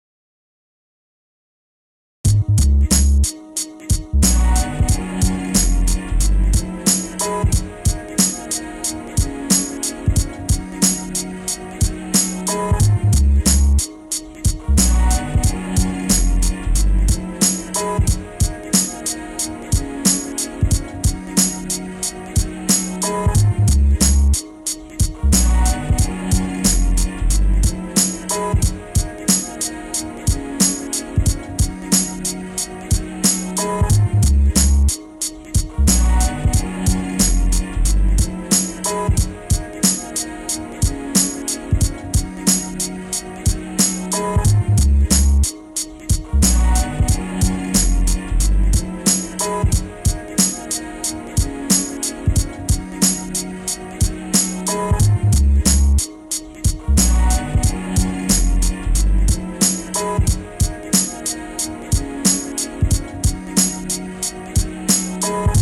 I’am pushing the input on the J37 hard . Testing the saturation